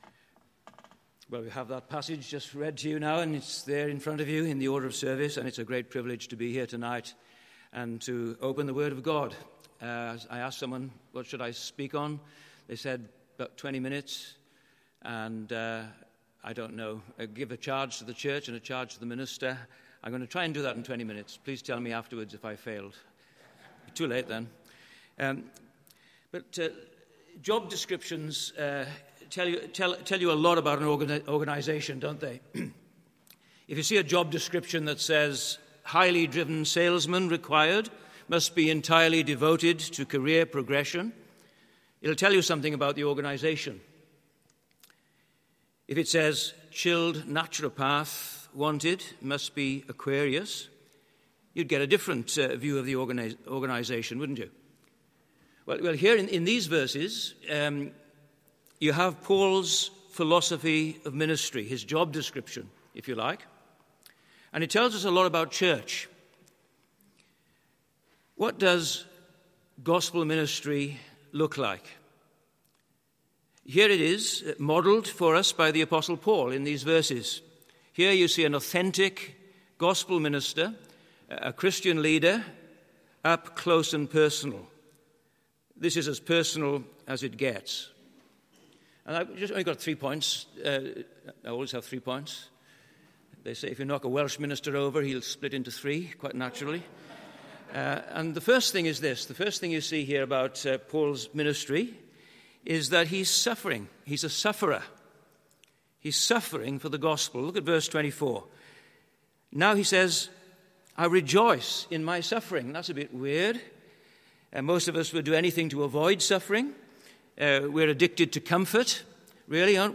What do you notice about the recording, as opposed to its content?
The Scots’ Church Melbourne 11am Service 19th of April 2021